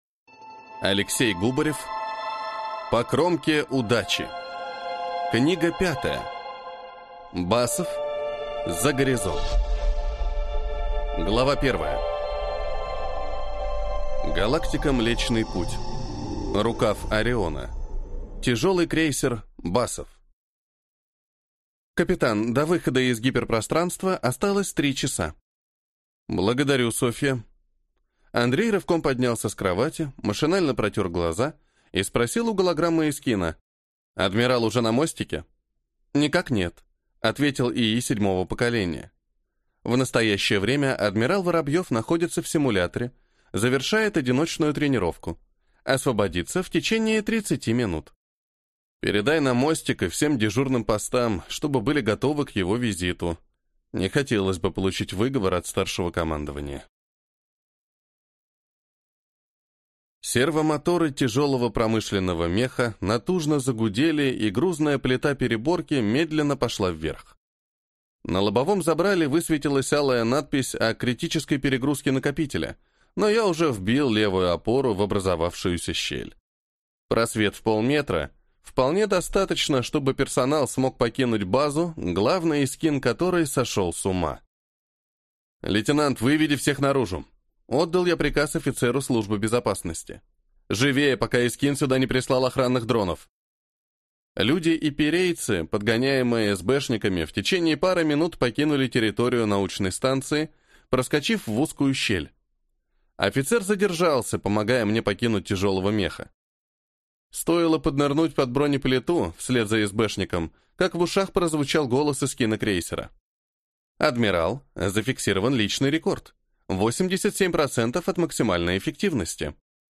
Аудиокнига По кромке удачи. Басов, за горизонт | Библиотека аудиокниг